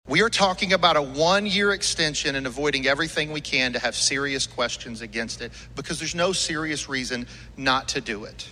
CLICK HERE to listen to commentary from Floor Leader Jon Echols.